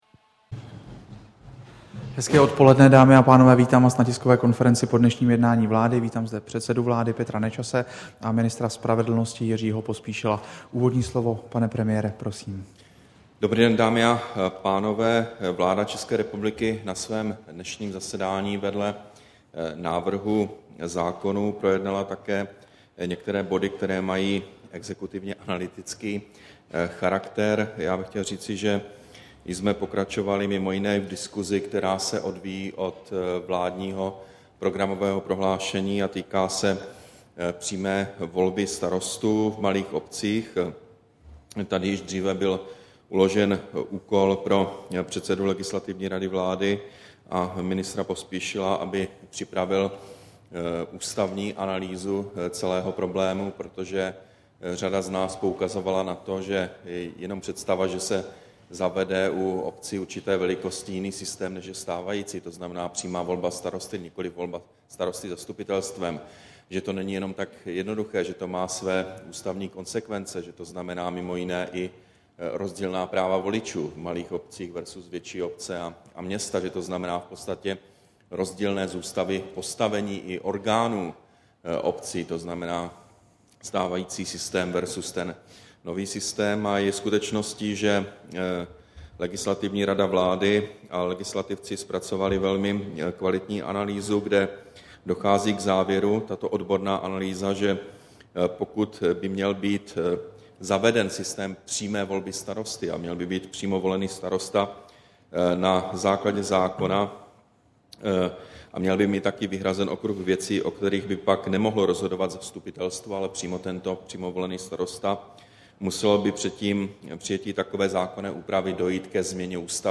Tisková konference po jednání vlády, 2. června 2011